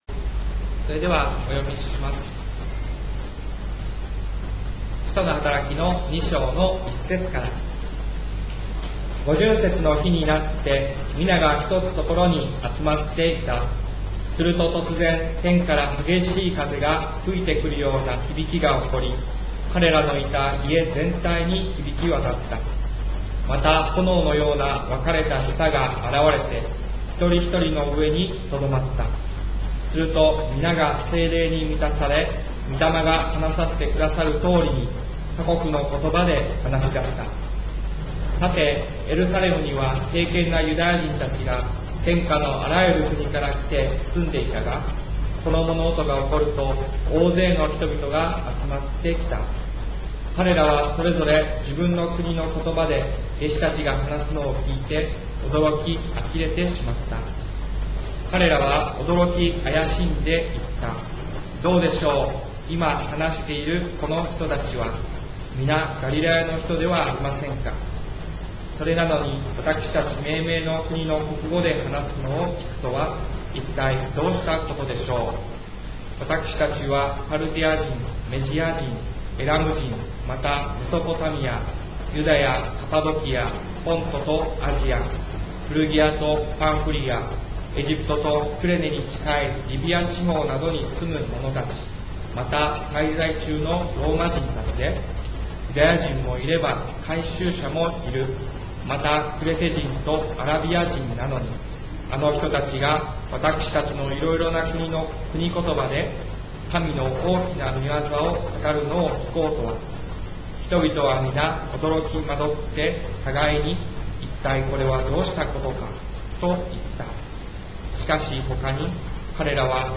礼拝メッセージ集 - タイ聖書福音教会